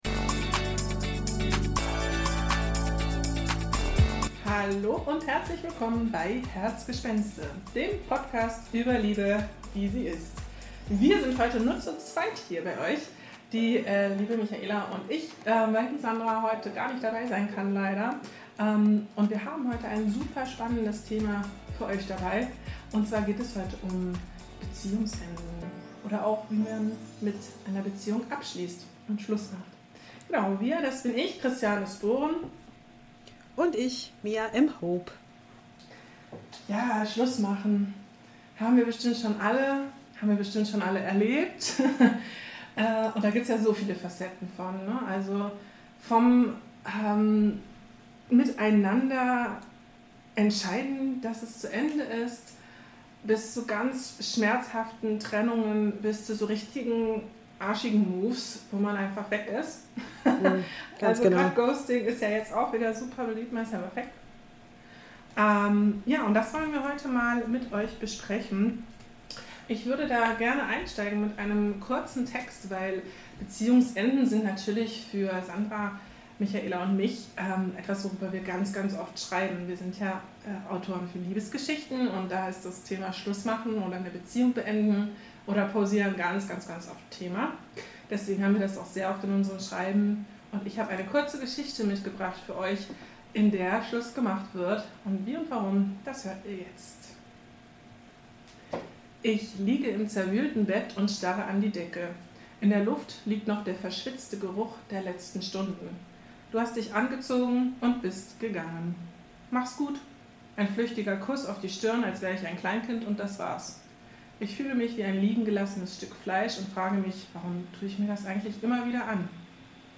Wir reden ehrlich darüber, warum es so schwer ist, einen sauberen Cut zu machen, ob es wirklich Trennungen ohne Drama gibt – und warum man Liebeskummer nicht immer verhindern kann. Trotz aller Herzschmerz-Themen wird viel gelacht – versprochen!